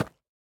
Minecraft Version Minecraft Version snapshot Latest Release | Latest Snapshot snapshot / assets / minecraft / sounds / mob / goat / step4.ogg Compare With Compare With Latest Release | Latest Snapshot